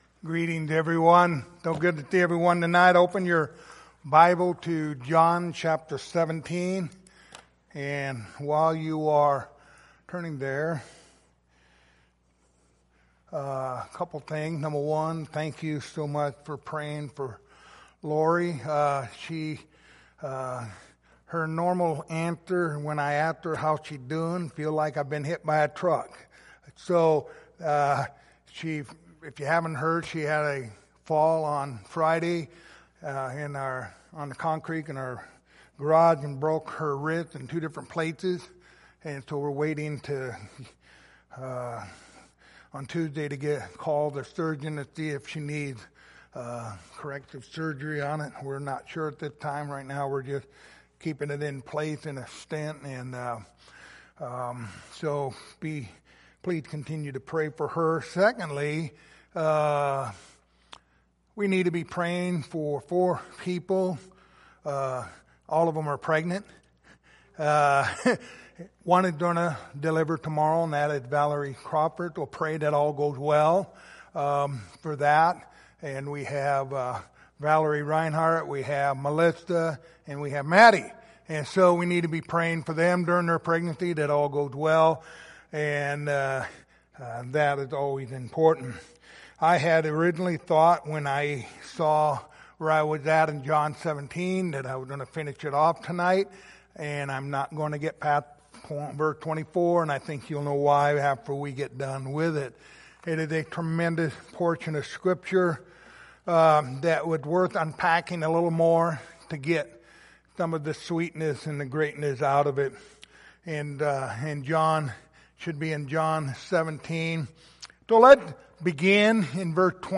Passage: John 17:24 Service Type: Lord's Supper Topics